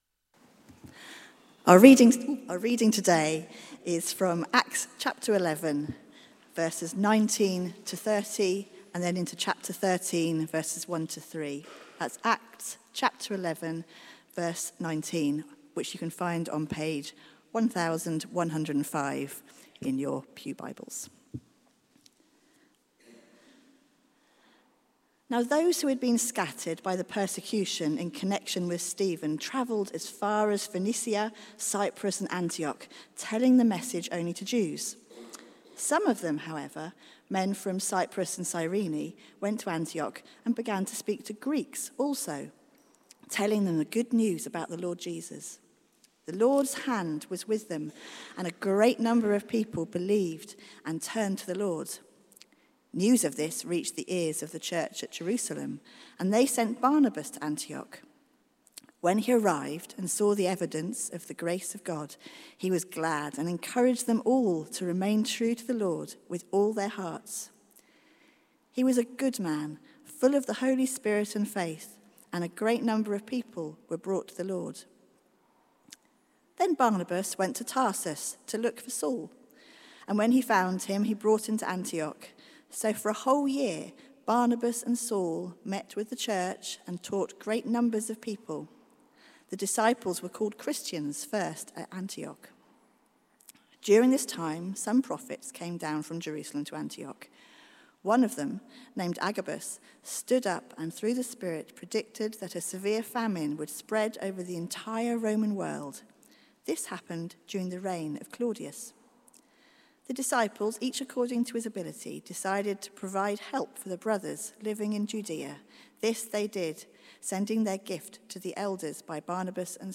Theme: Our Mother Church Sermon